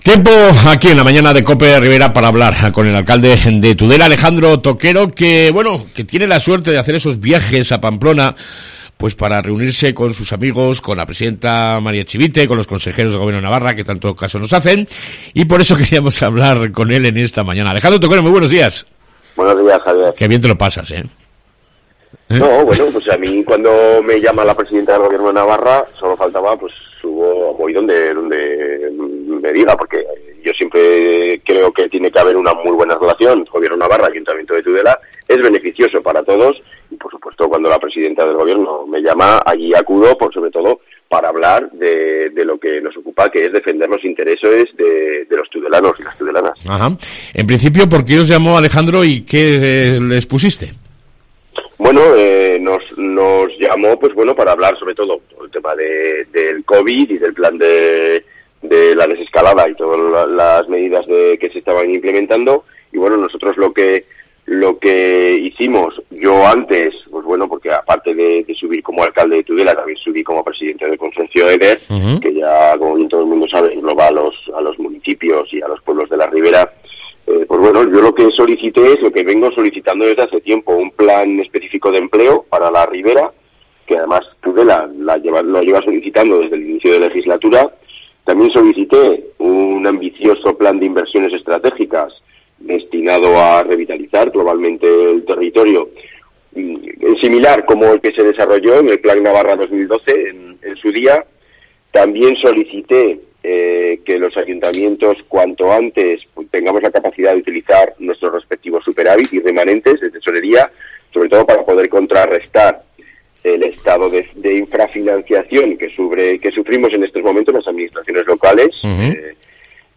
Entrevista con Alejandro Toquero